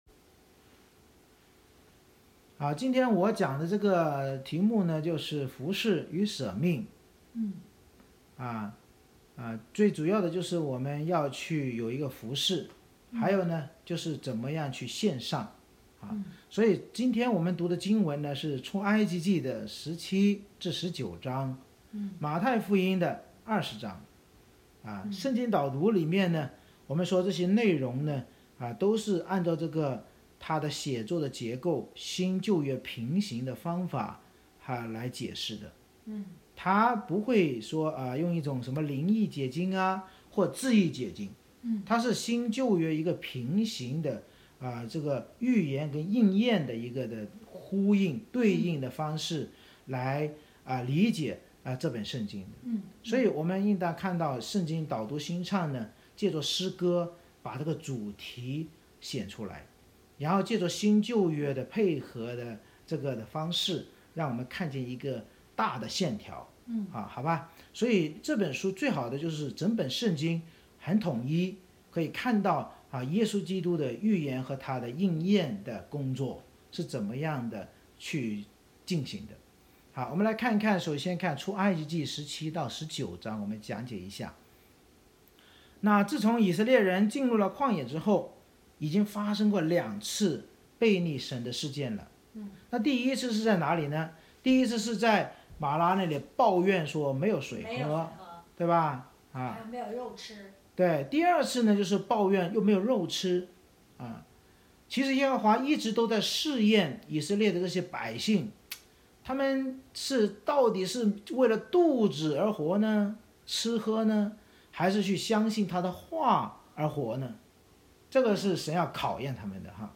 每次崇拜以经文诗歌赞美开始，然后会众以接力方式读3-4章经文（中/英文）或角色扮演，并简单分享，最后由牧师藉着新书《圣经导读新唱365》的导读和新歌进行释经讲道。
出Exodus17-19，太Matthew20 Service Type: 主日崇拜 每次崇拜以经文诗歌赞美开始，然后会众以接力方式读3-4章经文（中/英文）或角色扮演，并简单分享，最后由牧师藉着新书《圣经导读新唱365》的导读和新歌进行释经讲道。